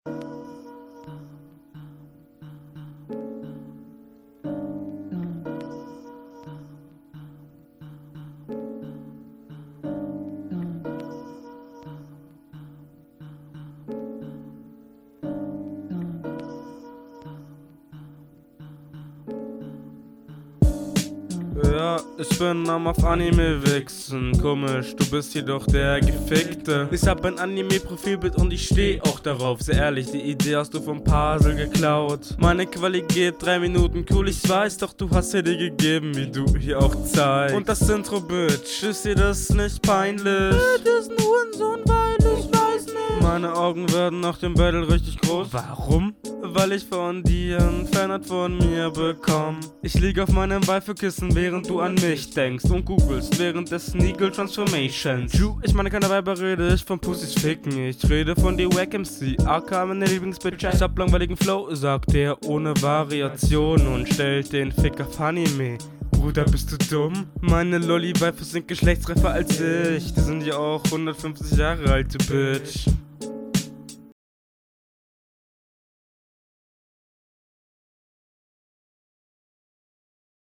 Der Stimmeinsatz wirkt bisschen seltsam, du klingst teilweise so als würdest du gequält werden.
Flow: ➨ Kommst nicht ganz so gut auf dem Beat klar, wie der Gegner.